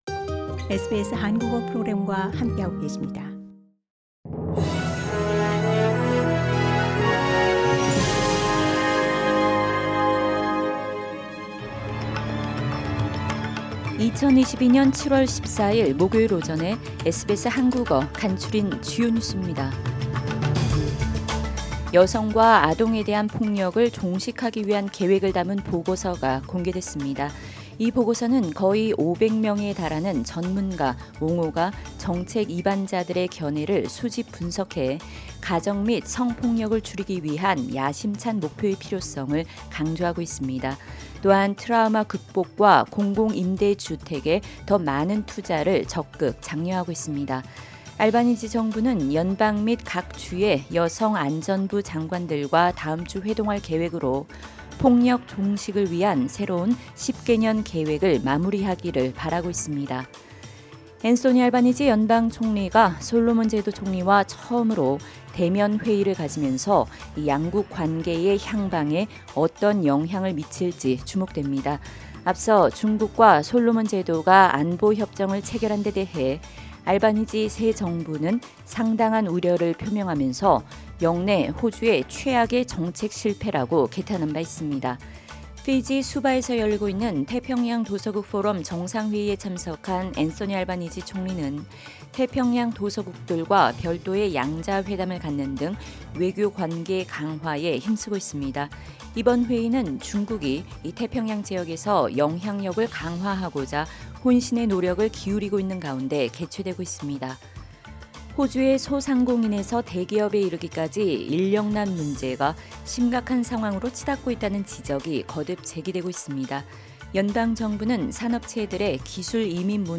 SBS 한국어 아침 뉴스: 2022년 7월 14일 목요일